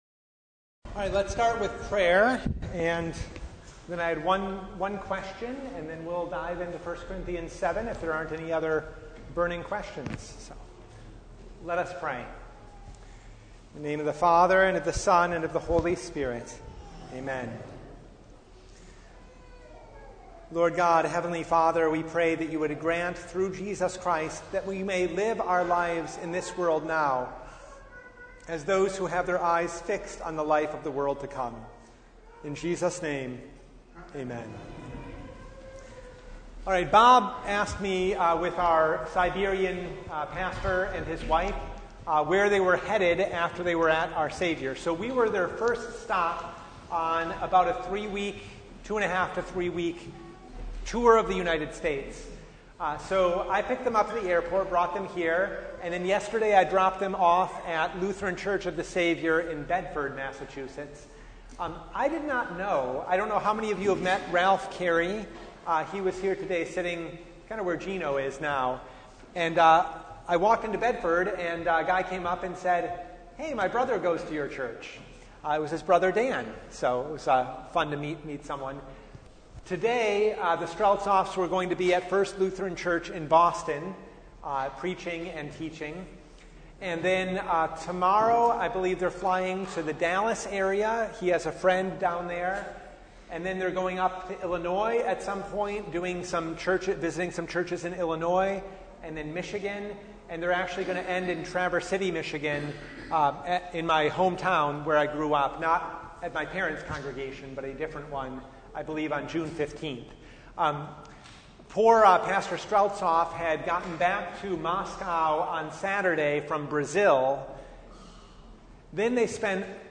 Bible Study « The Seventh Sunday of Easter